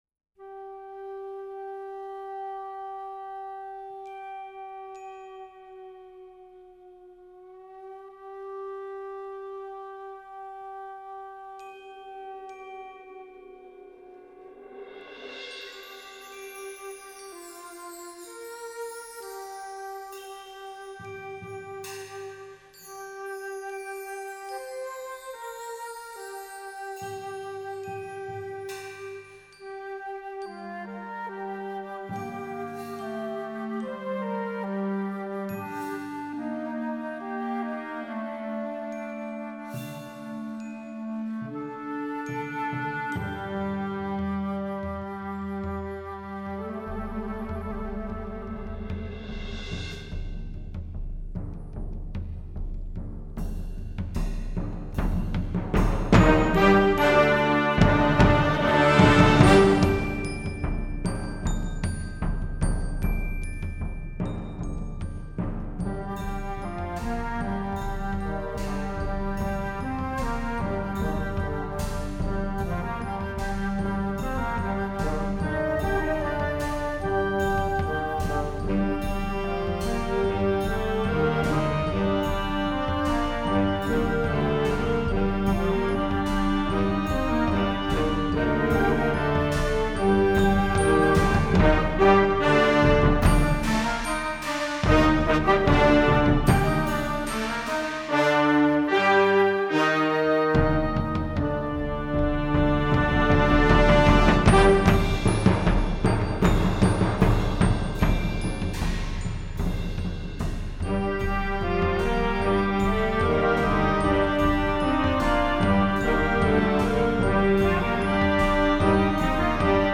Instrumentation: concert band
instructional, children